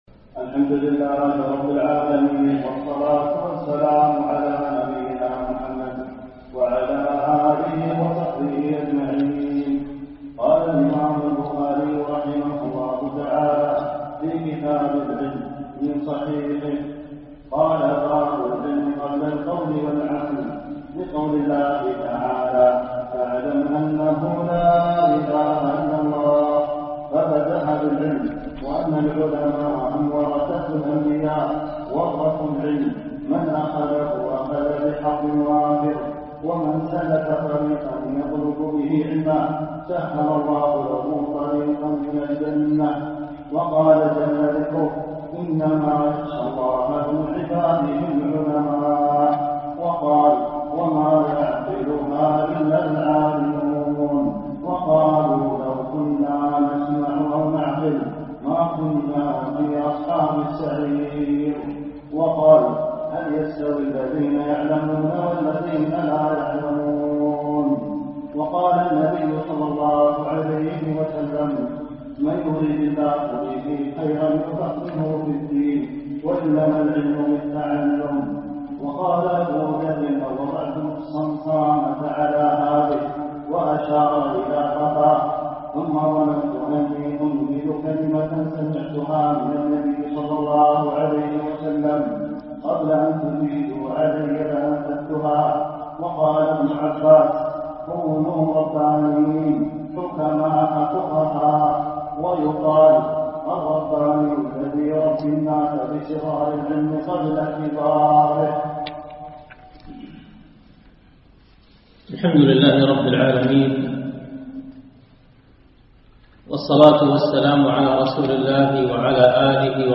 الدرس الرابع
دروس مسجد عائشة